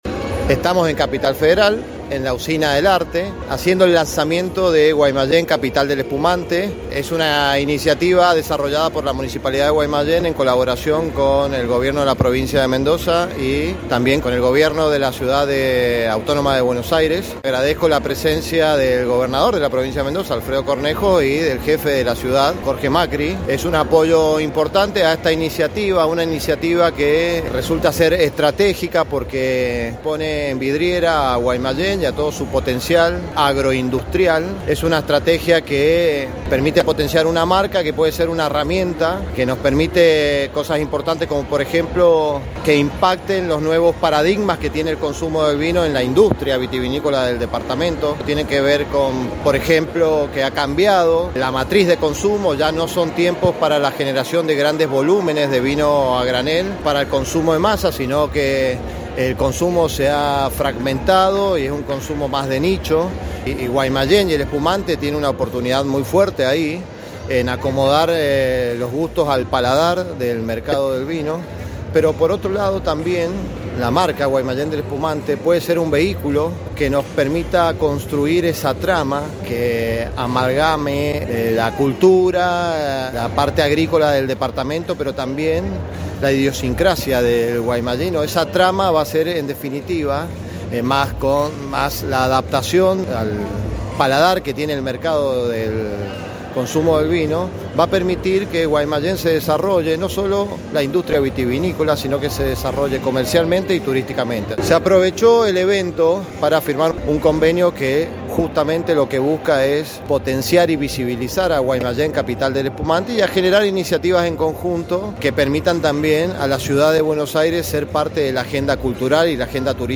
Escuchá la entrevista al intedente sobre la firma de convenio y la presentación de la Capital del Espumante en CABA: